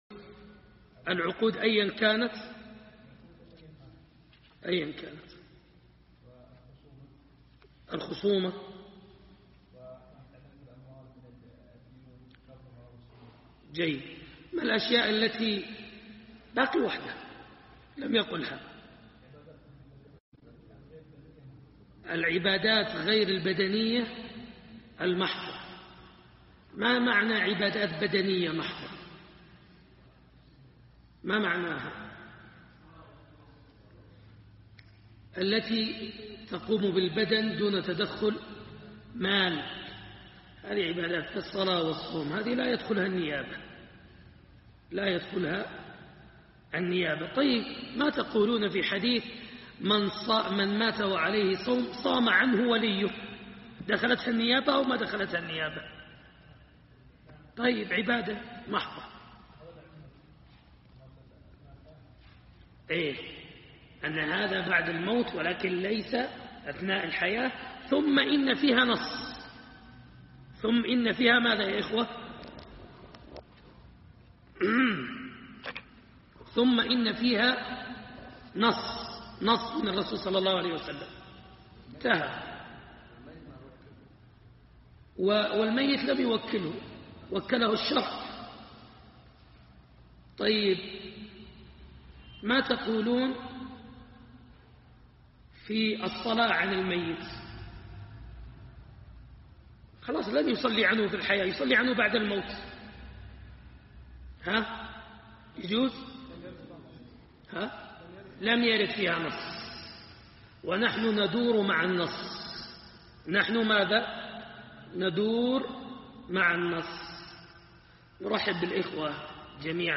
درس الفقه - باب الكفالة